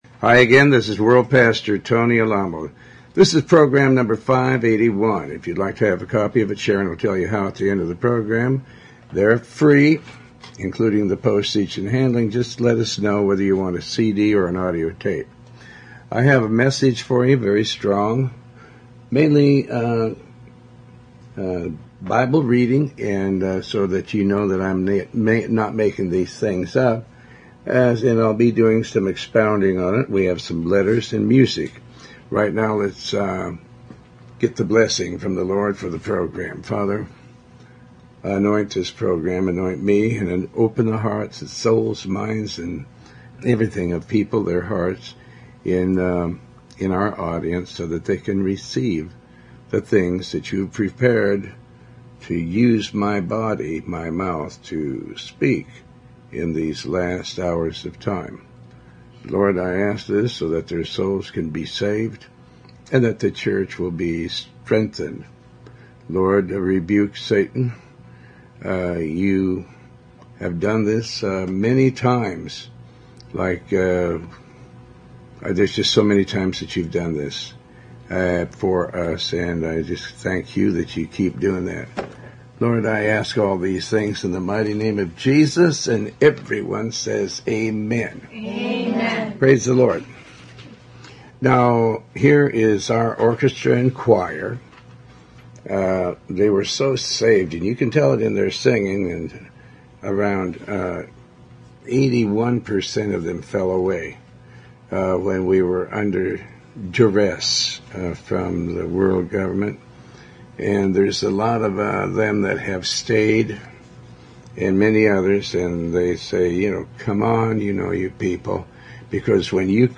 Talk Show Episode, Audio Podcast, Tony Alamo and Judgement for Judges - Program 581 on , show guests , about Program 581,judgement for judges, categorized as Health & Lifestyle,History,Love & Relationships,Philosophy,Psychology,Christianity,Inspirational,Motivational,Society and Culture